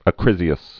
(ə-krĭzē-əs)